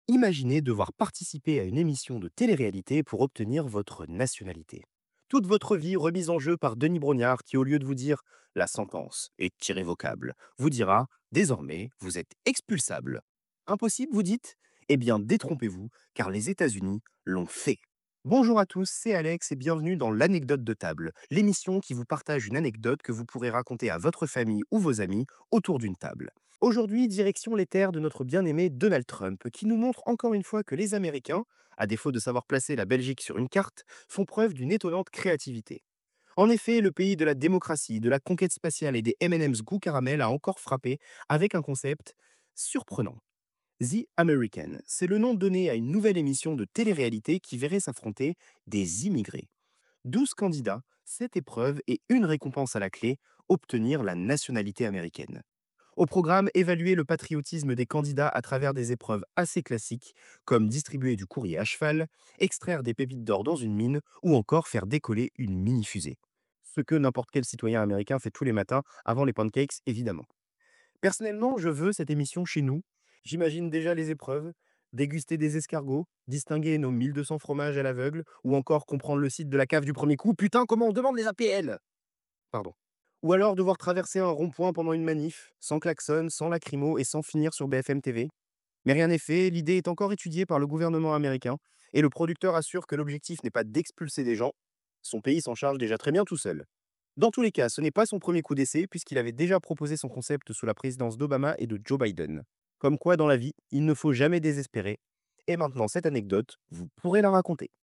Bandes-son
Présentation